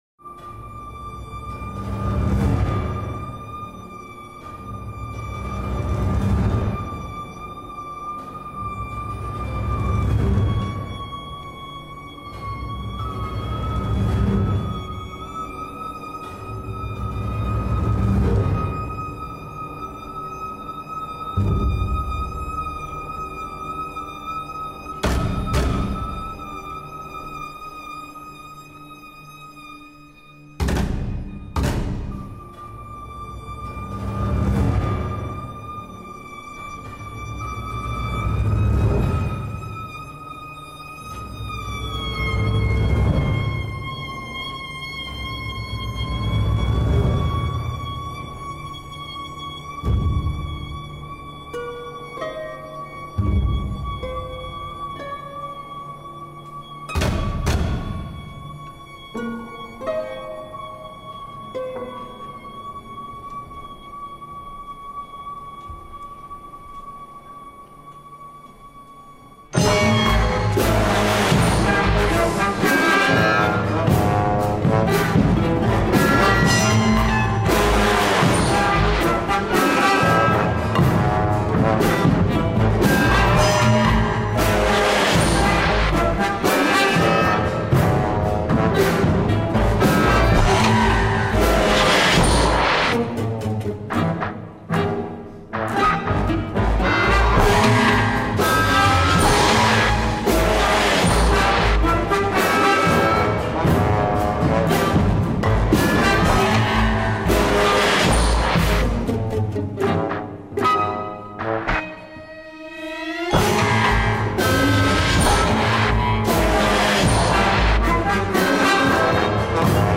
for orchestra and sample-keyboard